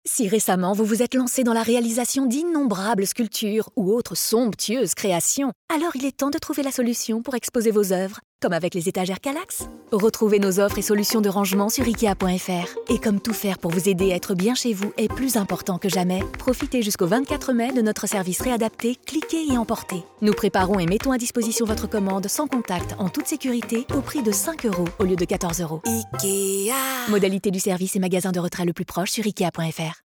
VOIX OFFICIELLE PUBLICITE RADIO IKEA